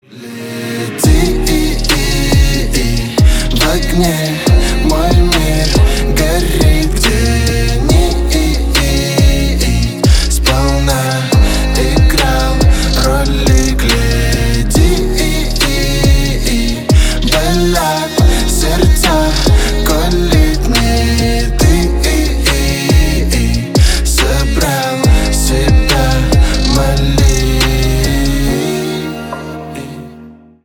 Рэп и Хип Хоп
спокойные